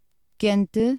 Ääntäminen
UK : IPA : [ˈhɒt] US : IPA : [ˈhɑt]